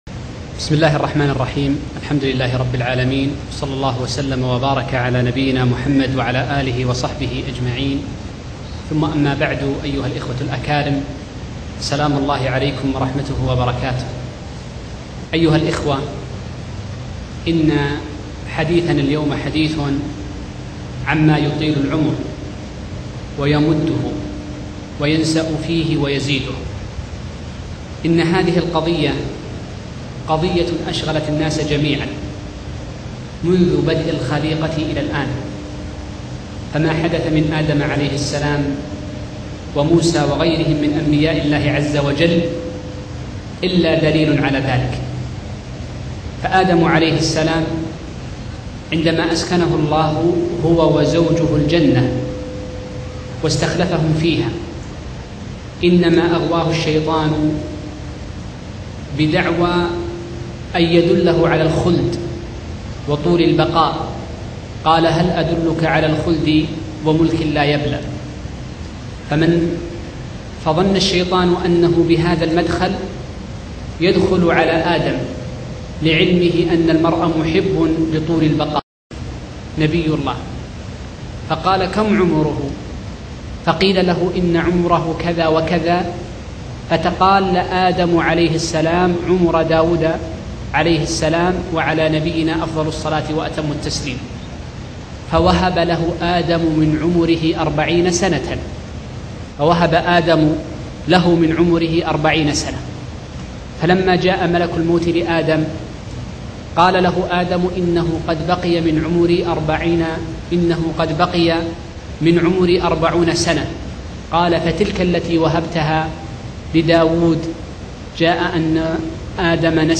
محاضرة نافعة - كيف تطيل عمرك ؟